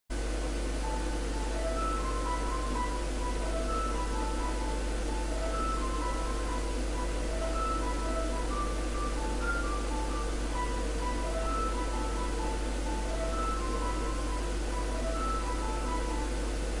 Sorry for the very bad quality.